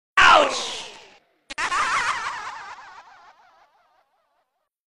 ouch c00l Meme Sound Effect